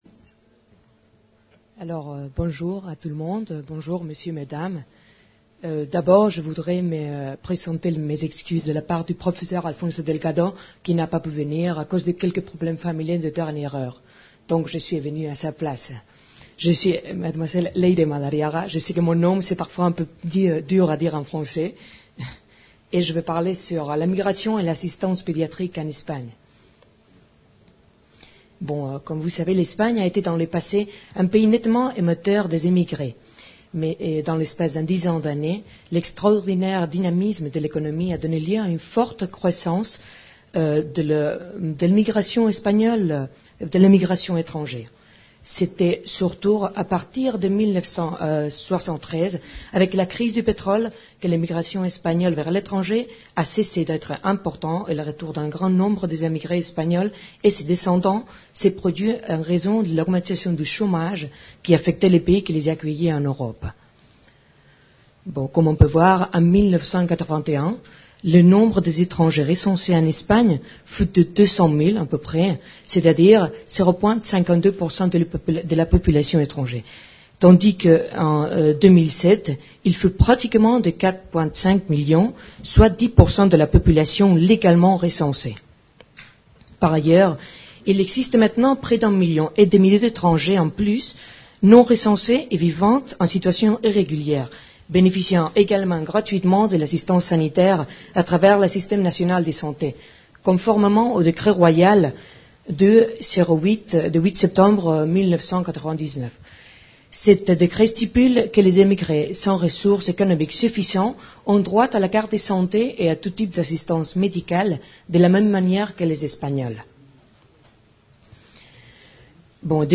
Face à cette nouvelle réalité, comment le gouvernement espagnol a-t-il réagi ? Quelles mesures a-t-il pris en matière d’accès au système de santé ? La conférence a été donnée à l'Université Victor Segalen Bordeaux 2 à l’occasion de la séance délocalisée de l’Académie Nationale de Médecine « Bordeaux, Porte Océane » le 15 avril 2008.